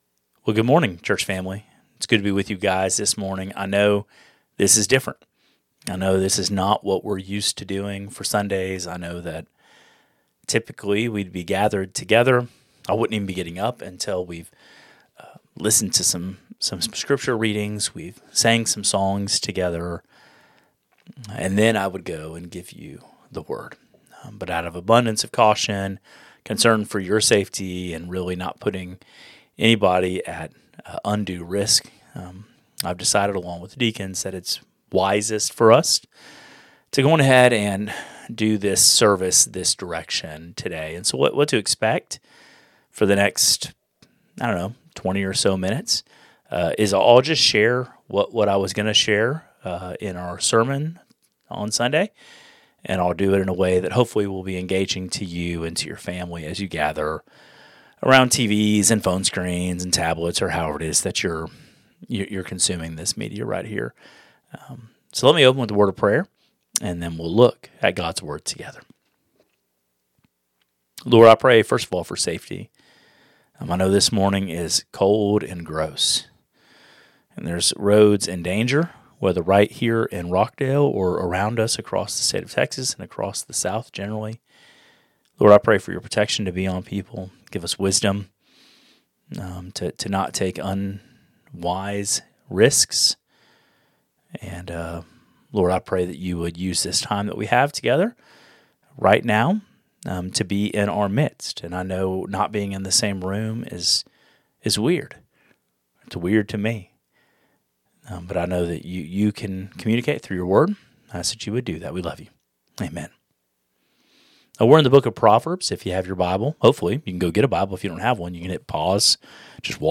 Thanks to the ice storm we are all at home today, but God still has a message for us today from Proverbs 2. Today we will see how valuable wisdom is to those who seek it.